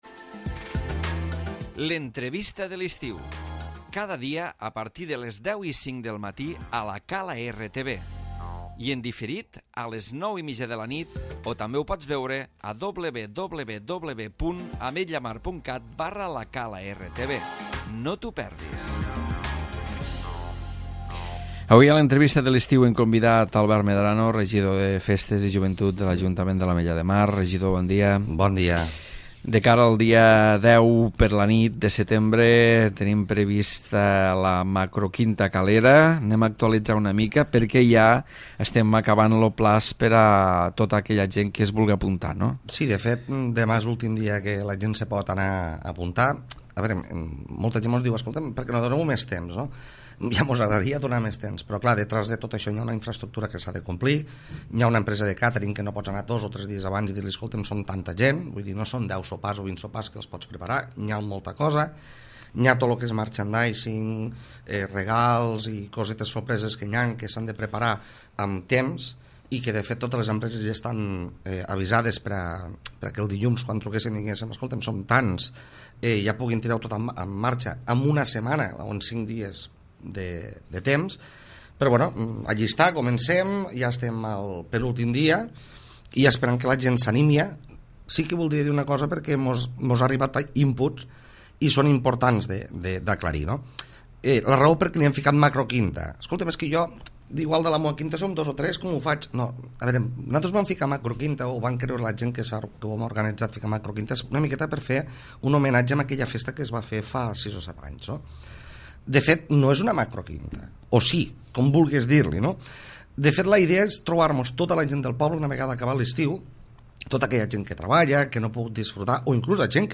L'Entrevista
Albert Medrano, regidor de Festes i Joventut parla de la Macroquinta Calera, festa organitzada pel dia 10 de setembre a la nit.